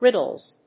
発音記号
• / ˈrɪdʌlz(米国英語)